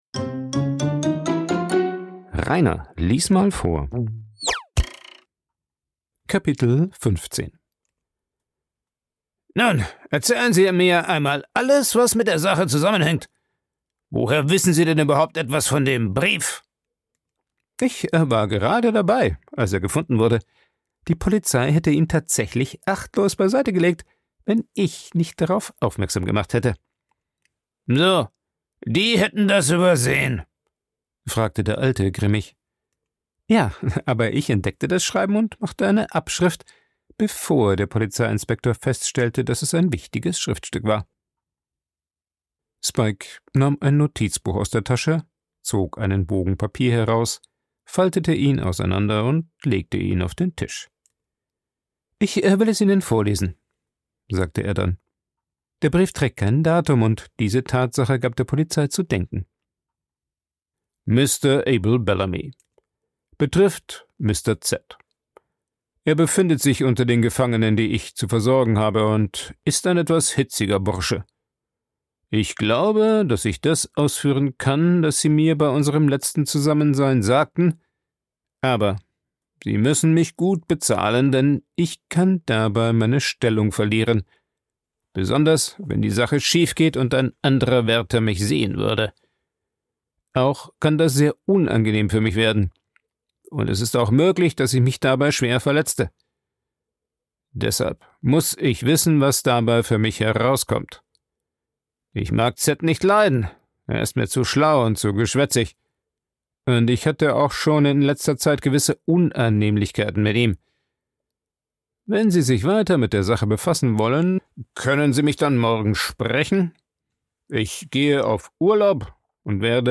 Ich lese Bücher vor, vorwiegend Klassiker der Weltliteratur, weil ich Spass am Vorlesen habe. Jeden 2. oder 3. Tag wird ein Kapitel eines Buches veröffentlicht, so dass mit der Zeit komplette Hörbücher entstehen.